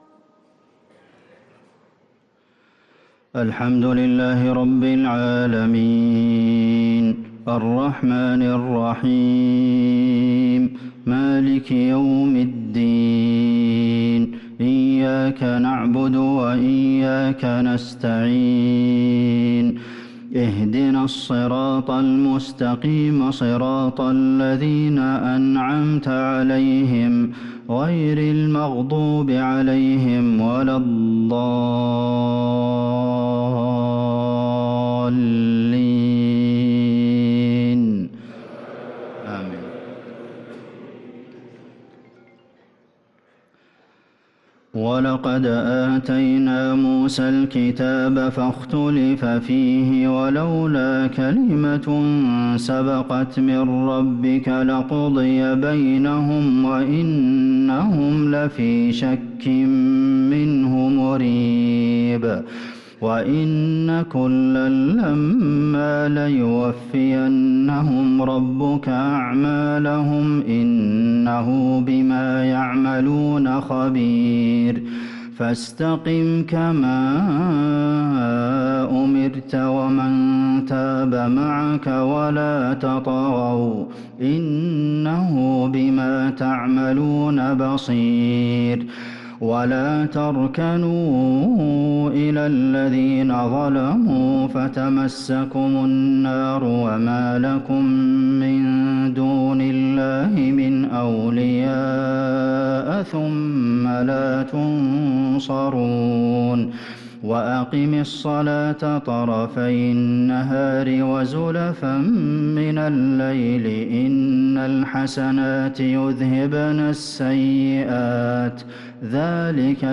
صلاة العشاء للقارئ عبدالمحسن القاسم 7 رجب 1444 هـ